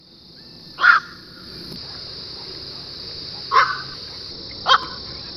Black-crowned Night-Heron
VOICE: The call is a loud, deep, squawking note.